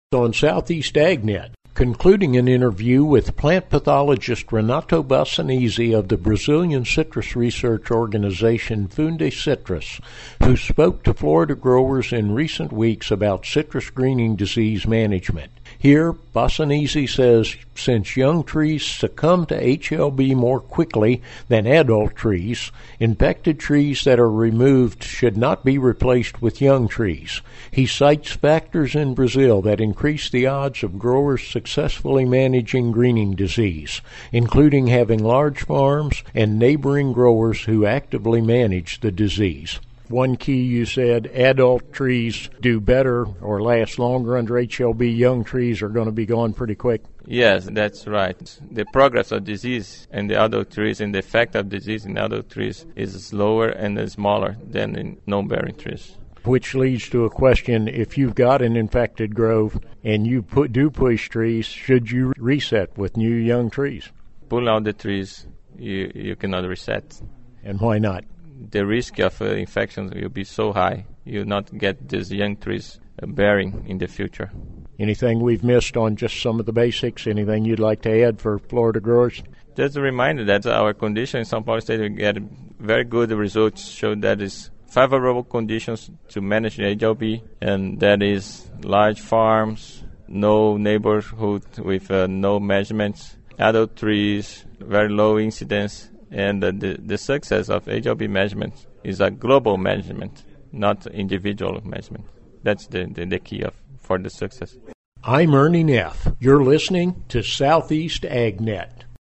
Plant pathologist